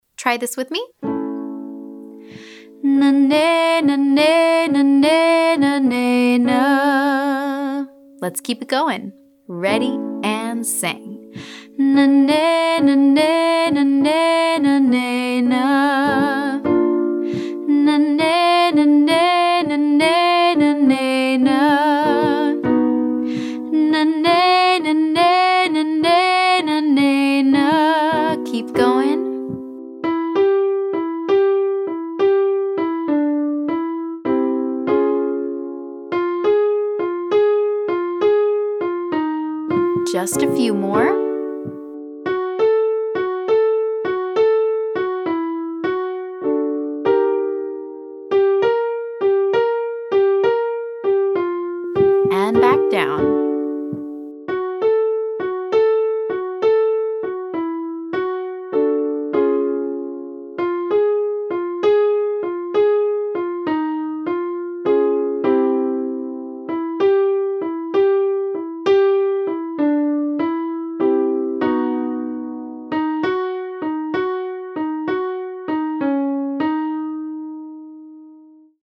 Day 11: Chest-dominant Mix
This is our chest-dominant mix, the mode we typically use for belting.